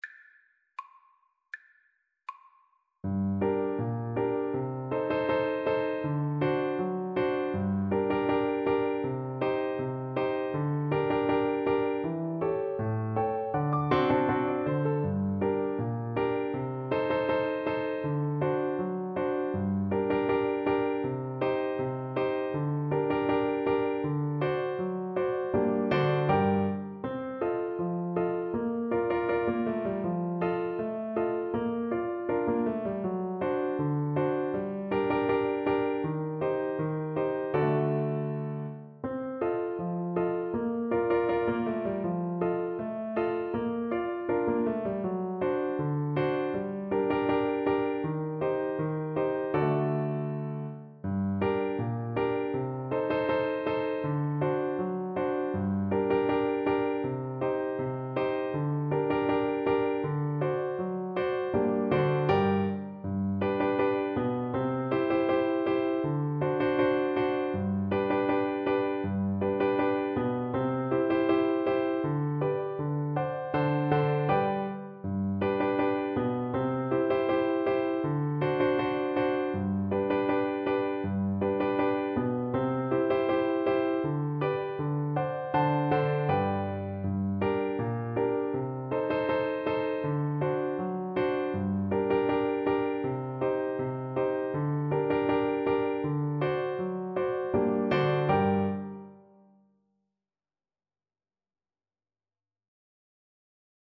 Classical Chiquinha Gonzaga Tamoio (Pas de quatre) Flute version
Flute
G minor (Sounding Pitch) (View more G minor Music for Flute )
=c.80 Andante
2/4 (View more 2/4 Music)
Classical (View more Classical Flute Music)
world (View more world Flute Music)